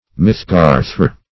Search Result for " mithgarthr" : The Collaborative International Dictionary of English v.0.48: Midgard \Mid"gard\ (m[i^]d"g[aum]rd), n. Also Midgarth \Mid"garth\ (-g[aum]r[th]), Mithgarthr \Mith"garthr\ (Icel. m[-e][th]"g[aum]r[th]r').